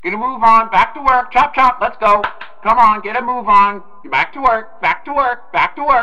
“Back to work, back to work!” meme sound, short clip for funny reactions, work jokes, and meme edits.